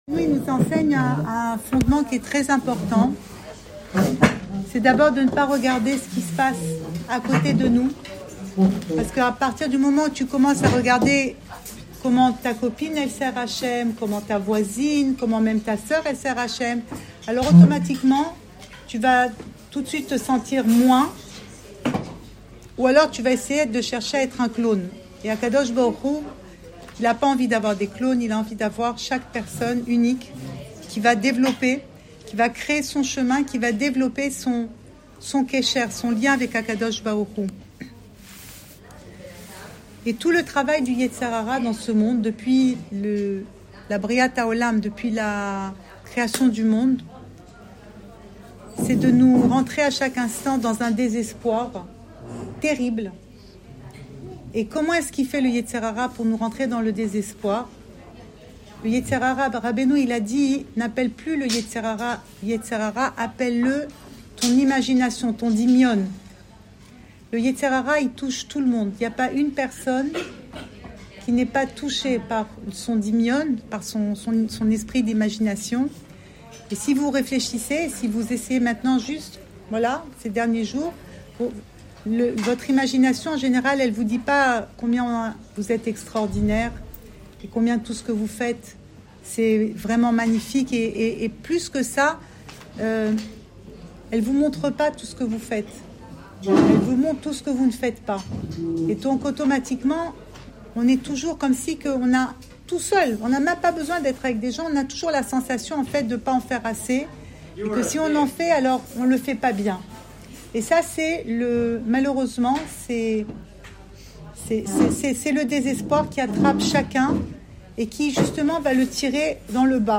Enregistré à Ouman – Tevet 5786